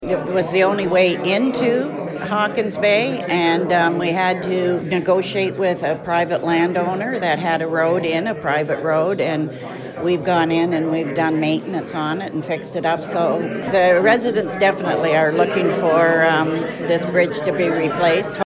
Tweed Mayor Jo-Anne Albert speaks at a funding announcement on July 29, 2019.